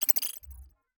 HiTech Click 1.wav